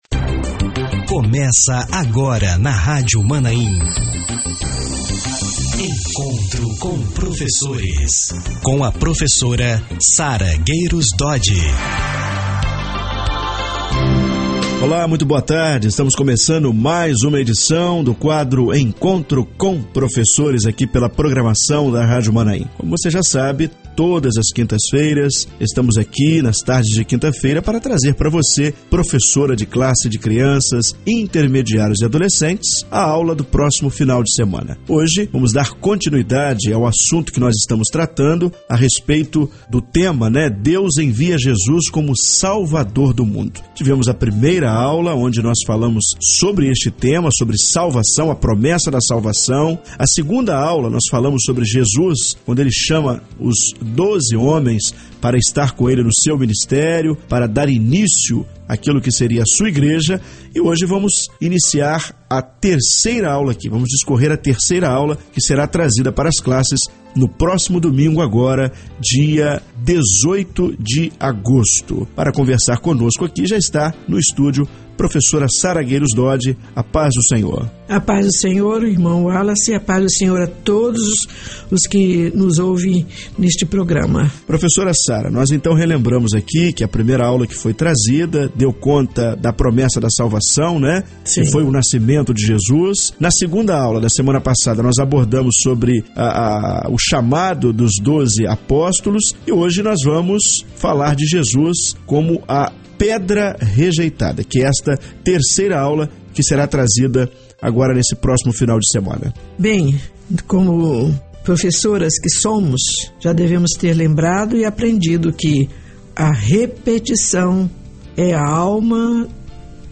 Ouça a terceira aula com a continuação desse assunto.